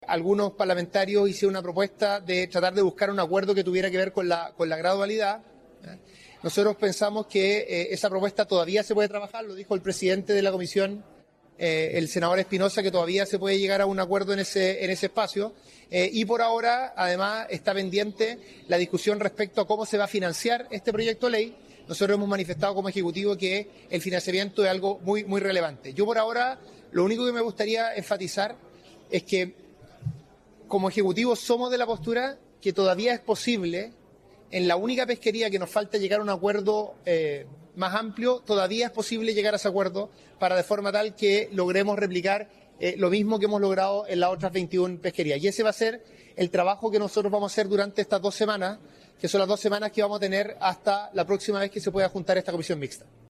Luego de la sesión el ministro de Economía reafirmó que “todavía es posible” llegar a un acuerdo en lo referido a las cuotas de merluza, acuerdo que buscarán alcanzar en las próximas dos semanas, antes que vuelva a sesionar la Comisión Mixta a fines de mayo.